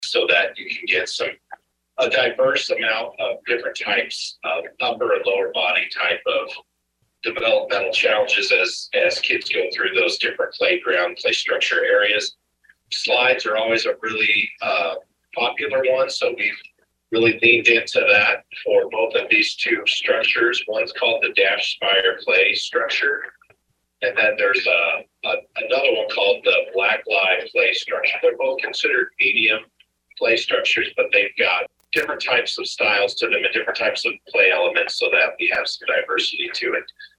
Speaking via video conference call